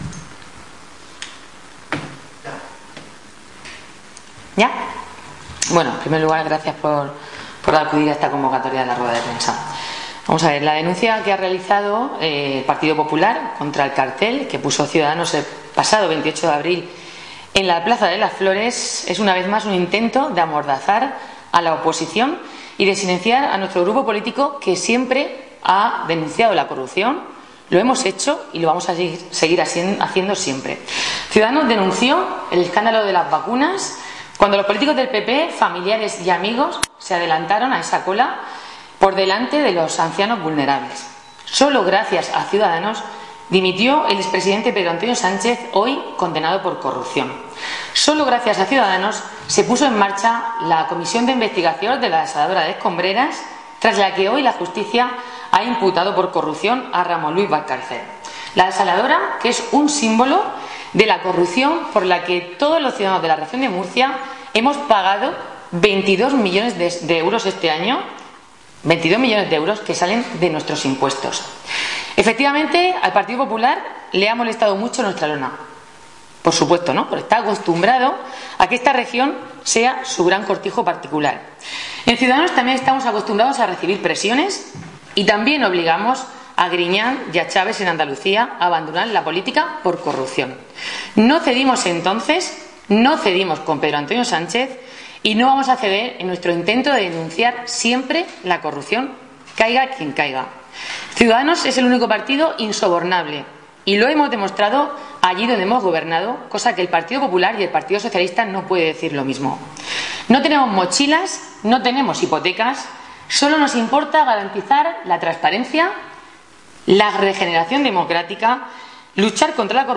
• La candidata a la presidencia de la Comunidad Autónoma, María José Ros, ha afirmado en rueda de prensa que el Partido Popular sigue creyendo que la Región de Murcia es su cortijo pero Ciudadanos siempre denunciará la corrupción, se pongan como se pongan, mientras López Miras ha puesto la mano en el fuego por Pedro Antonio Sánchez.